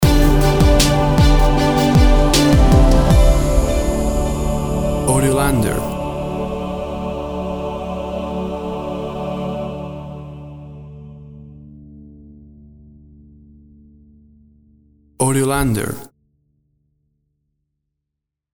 Tempo (BPM) 78